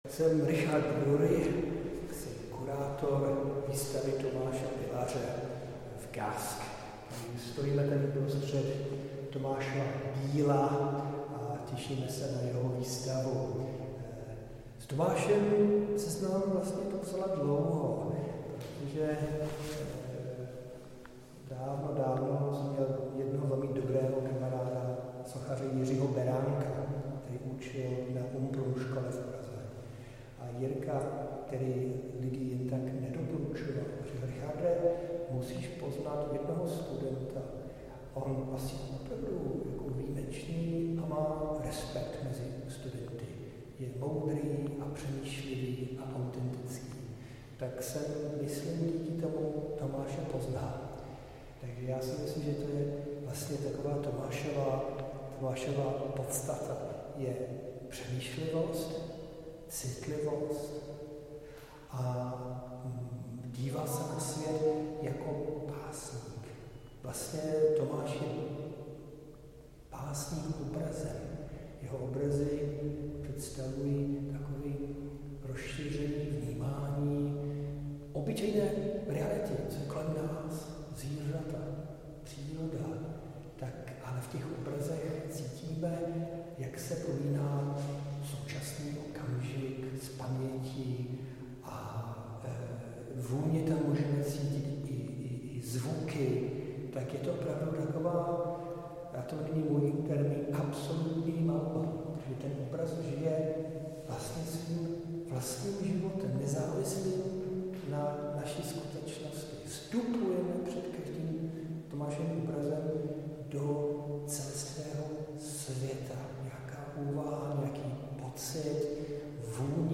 Audio prohlídka